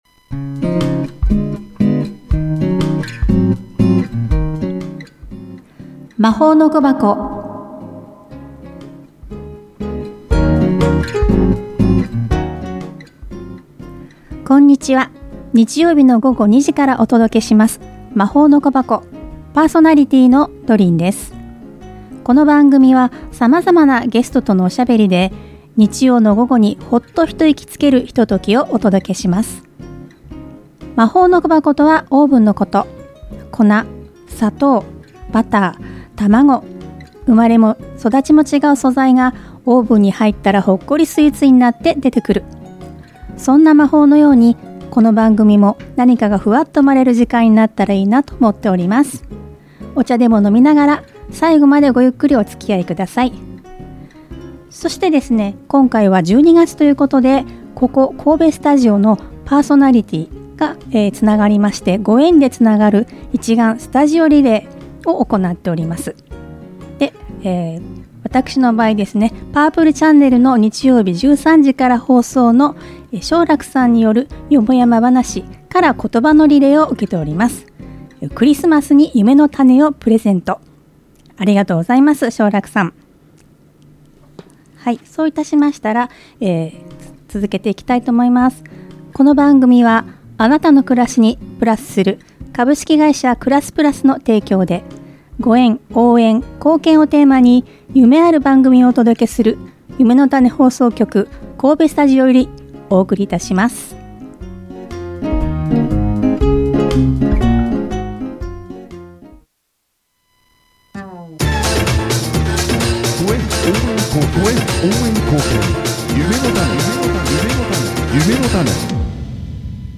⭐12 月の1人目のゲスト⭐